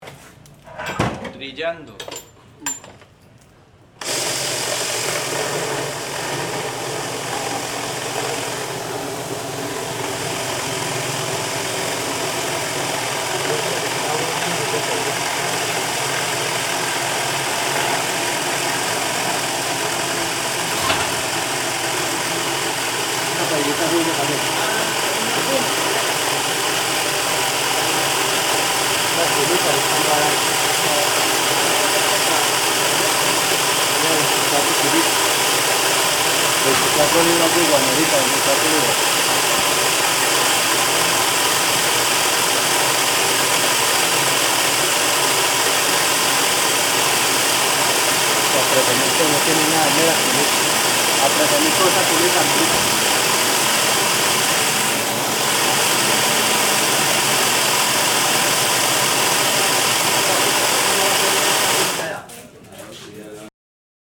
Trilla del café, Trujillo
Registro sonoro del proceso de producción de café en Trujillo, Valle del Cauca. Fase de trillar el café.